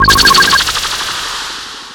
00026_Sound_Electron_pulses_3
1 channel